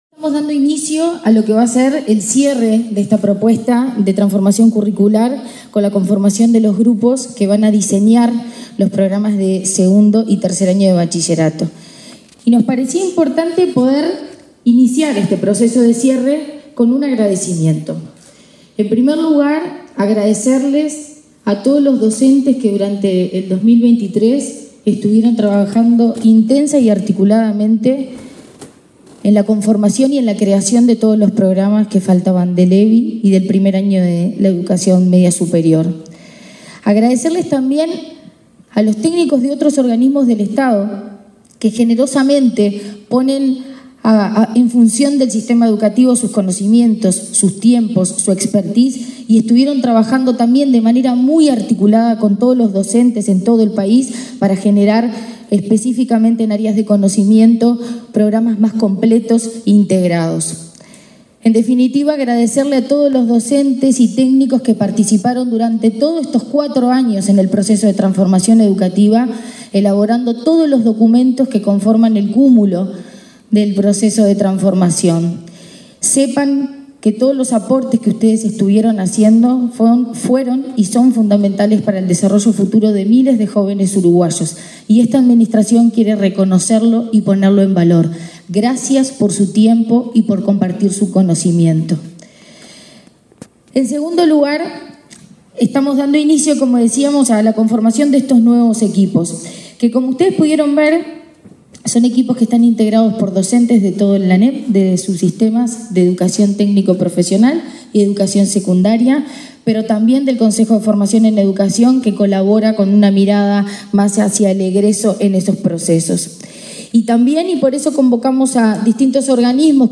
Palabras de la presidenta de la ANEP, Virginia Cáceres
La presidenta de la Administración Nacional de Educación Pública (ANEP), Virginia Cáceres, participó en Montevideo, en el lanzamiento de los equipos